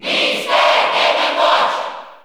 Crowd cheers (SSBU) You cannot overwrite this file.
Mr._Game_&_Watch_Cheer_Italian_SSB4_SSBU.ogg